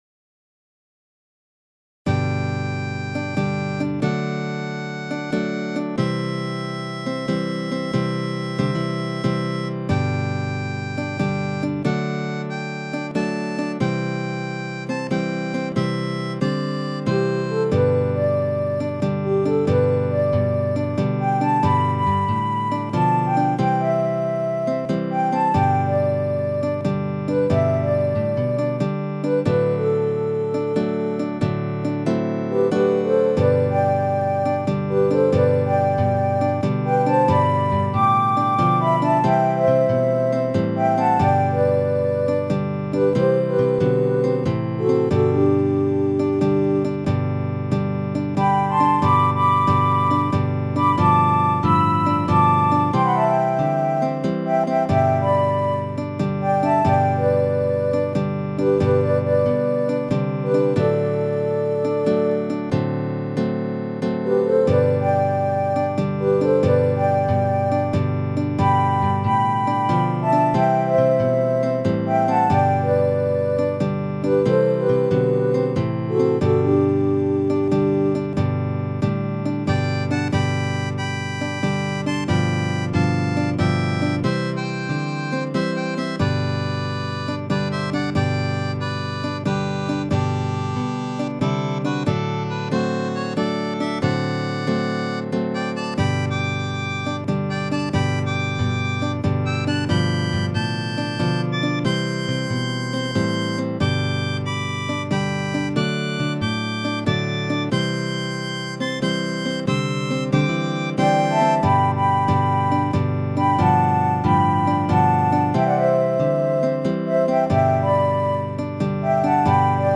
ほぼバンド時代のまま。